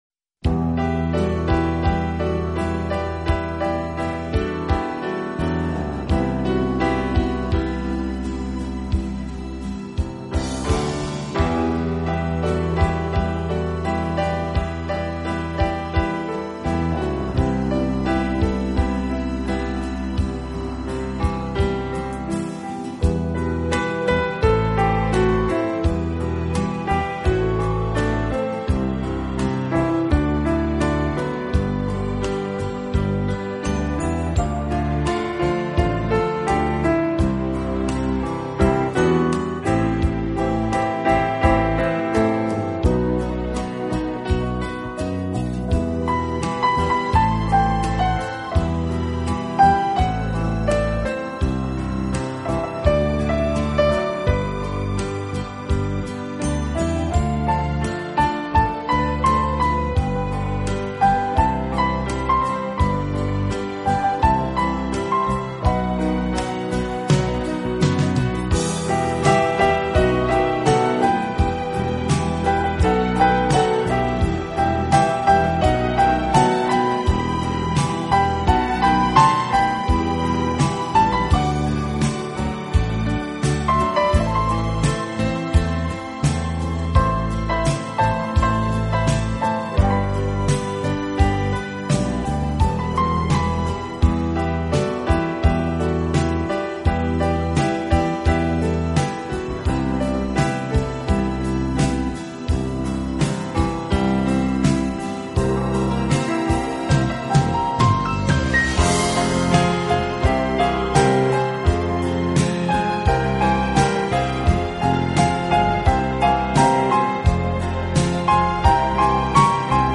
钢琴演奏版，更能烘托出复古情怀，欧美钢琴大师深具质感的演奏功力，弹指
本套CD全部钢琴演奏，